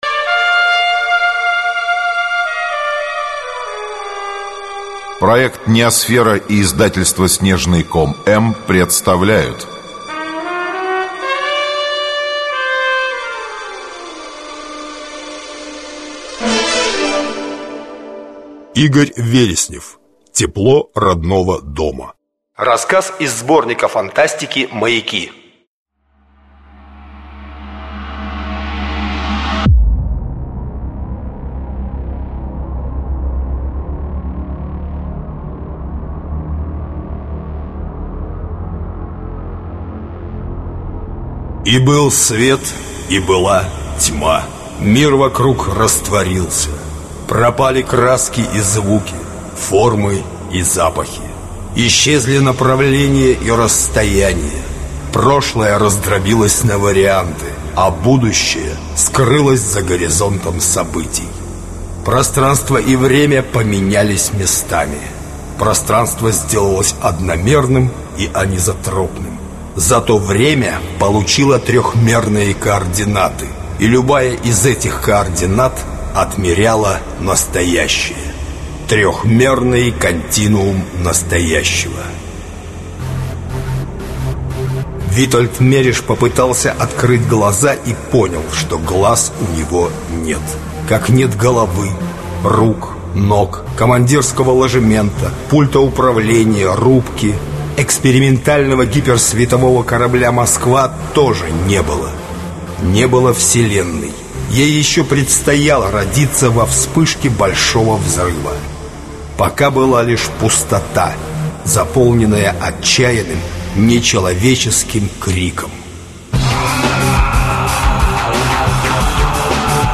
Аудиокнига Тепло родного дома | Библиотека аудиокниг
Aудиокнига Тепло родного дома Автор Игорь Вереснев Читает аудиокнигу Арт-группа NEOСФЕРА.